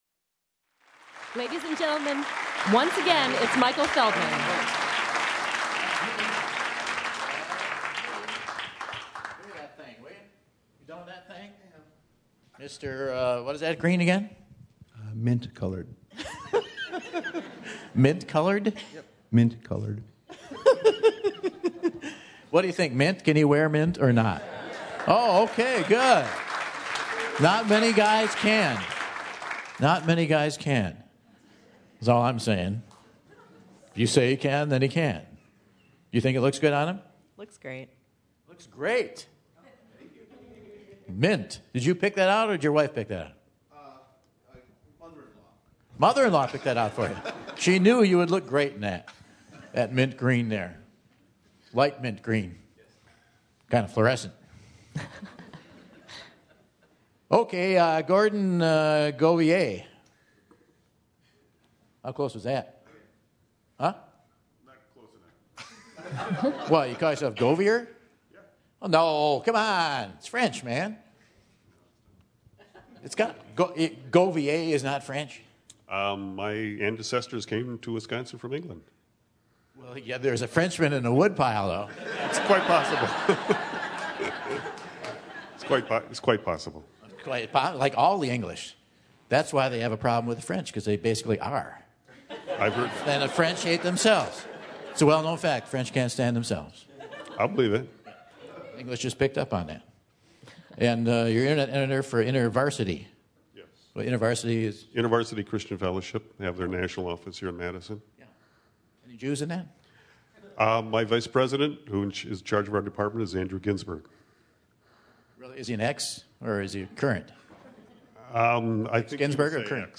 After checking the ice levels for fishing, Michael selects another contestant to play the Whad'Ya Know? quiz!